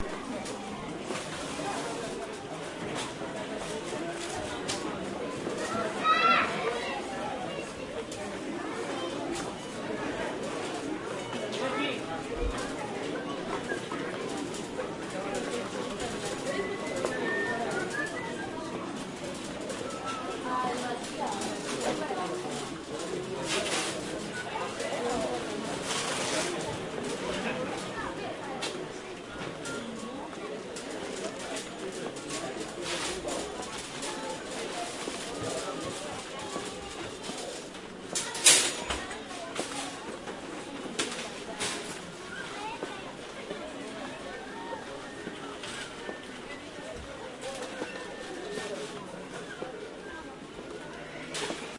На этой странице собраны звуки магазина игрушек: весёлая суета, голоса детей, звон кассы, фоновые мелодии.
Гул детских голосов в Детском мире